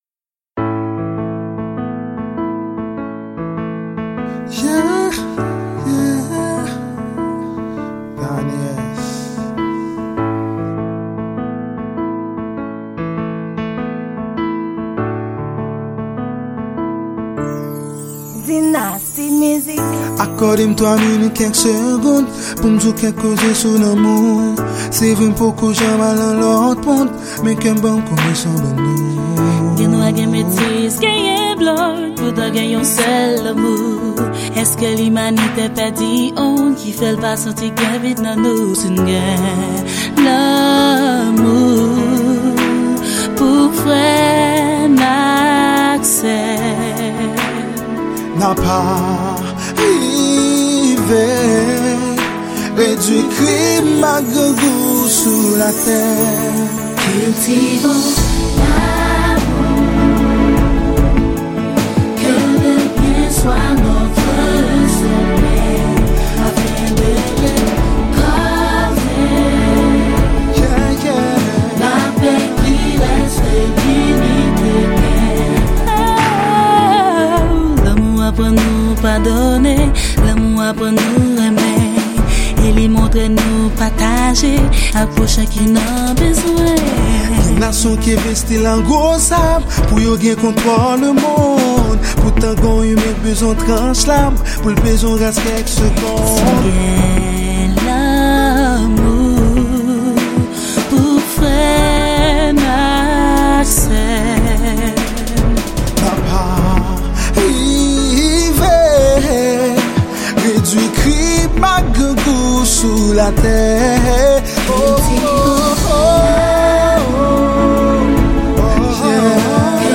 Genre: R& B.